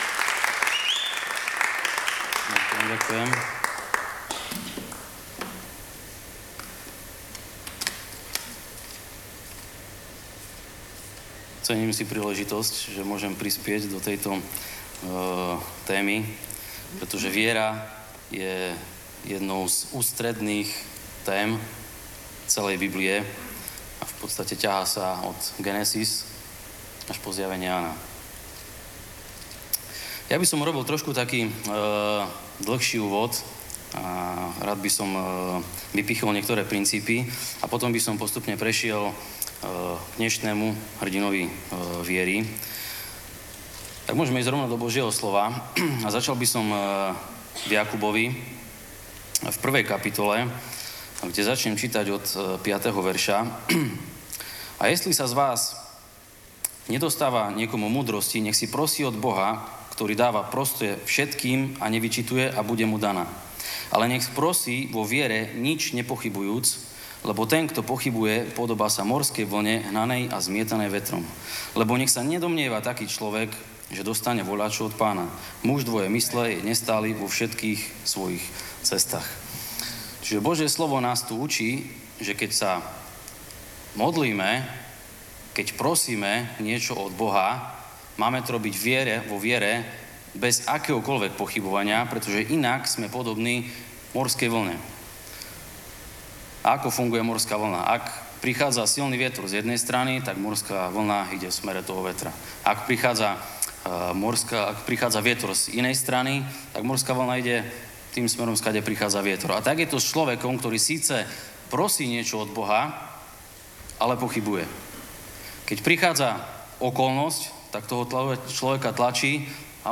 Audio kázeň